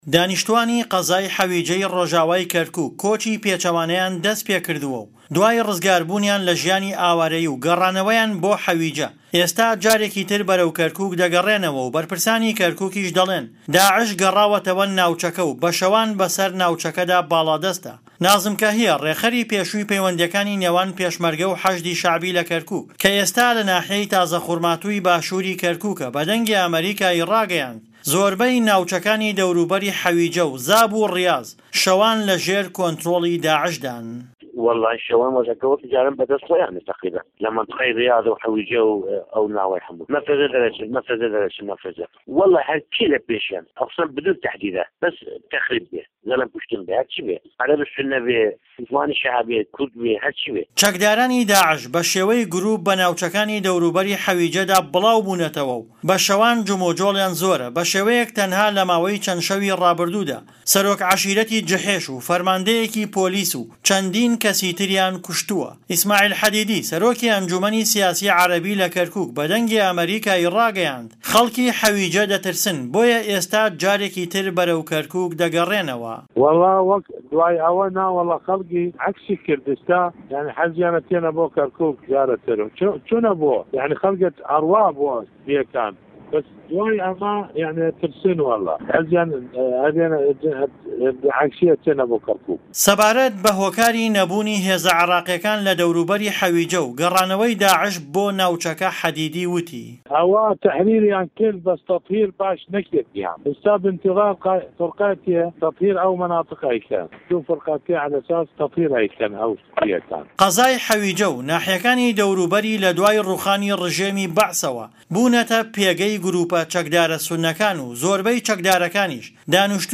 حه‌ویجه‌ - ڕاپۆرت